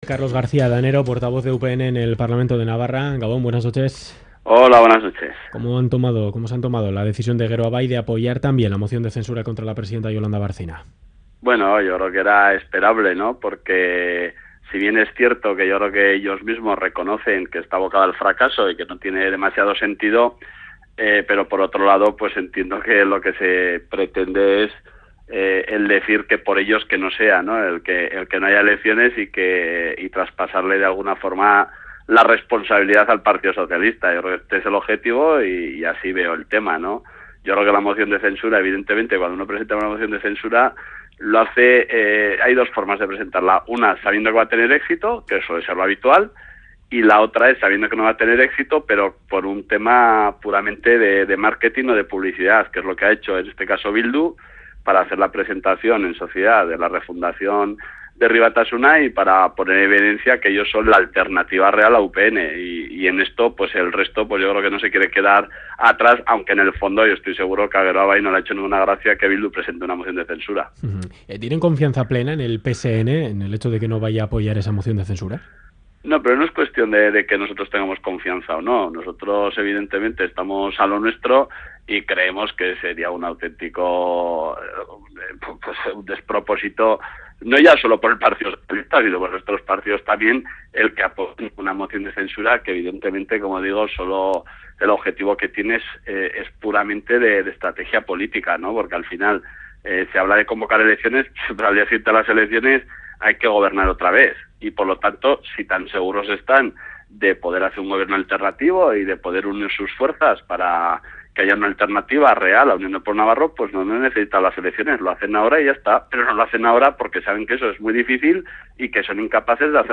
Carlos García Adanero, secretario general de UPN | Entrevista
El programa 'Ganbara' de Radio Euskadi entrevista a Carlos García Adanero, secretario general de UPN, sobre la moción de censura presentada por Bildu.